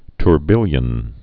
(tr-bĭlyən)